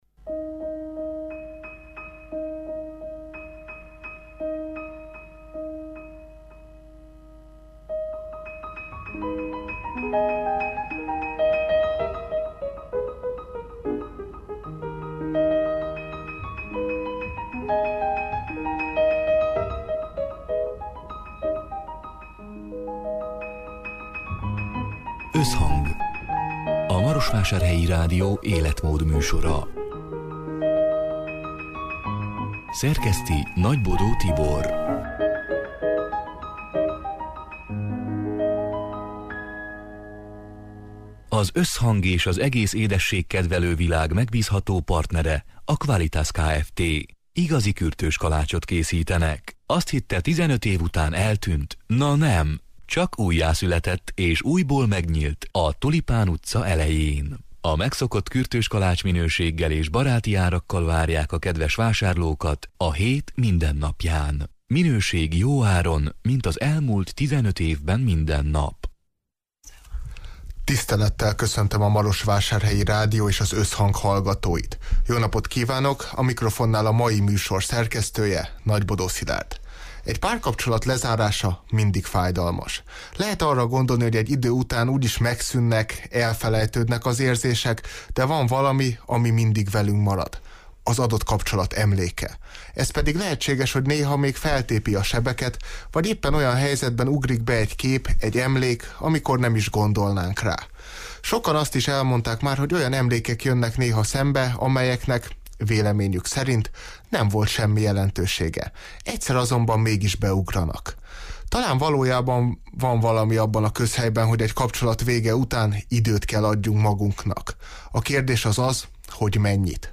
A Marosvásárhelyi Rádió Összhang (2022. szeptember 21-én, szerdán 18 órától élőben) c. műsorának hanganyaga: Egy párkapcsolat lezárása mindig fájdalmas.